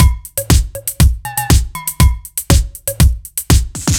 Index of /musicradar/french-house-chillout-samples/120bpm/Beats